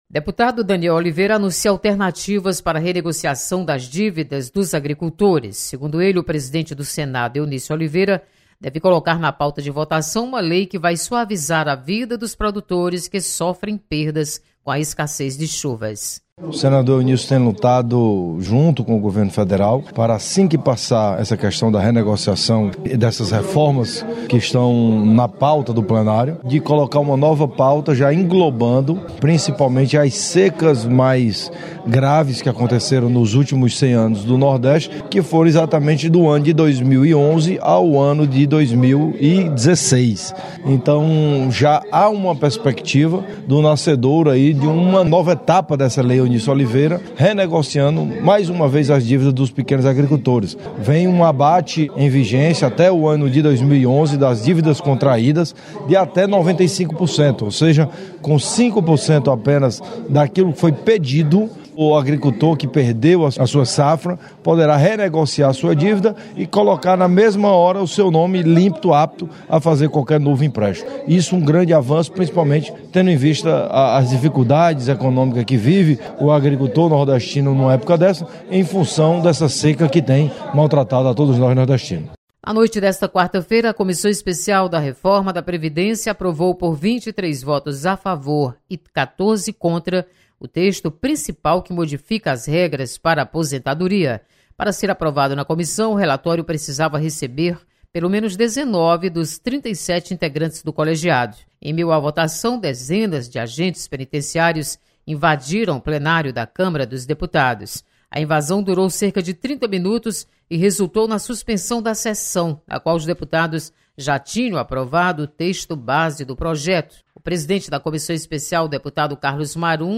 Deputado Danniel Oliveira destaca tramitação de projeto que prevê renegociação das dívidas dos agricultores.